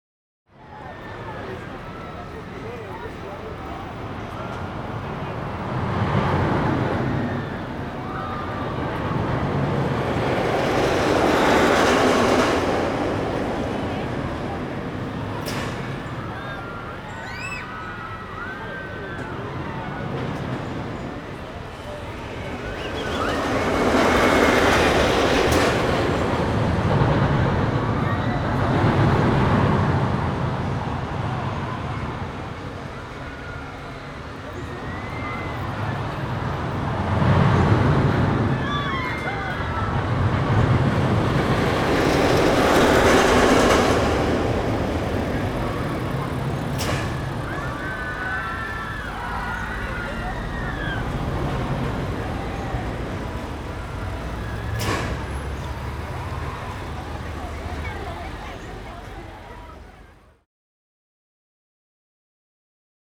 Roller Coaster.wav